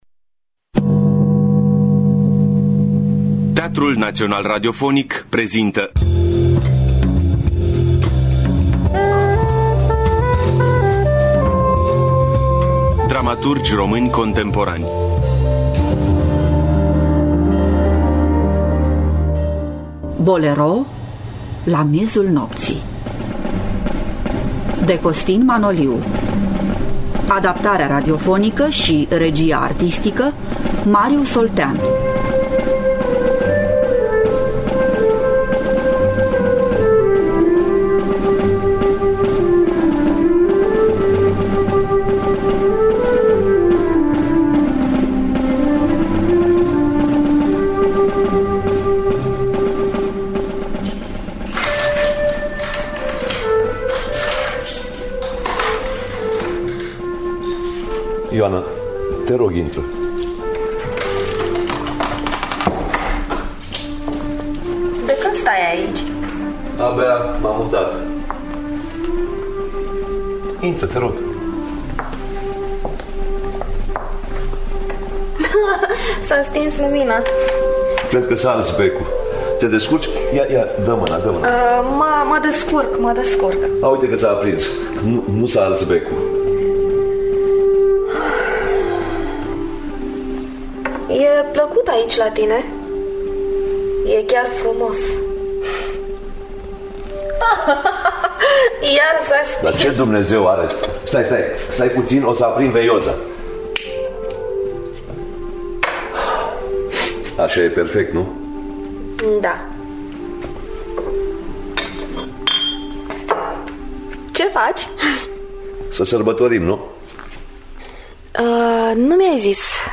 Bolero la miezul nopții de Ion-Costin Manoliu – Teatru Radiofonic Online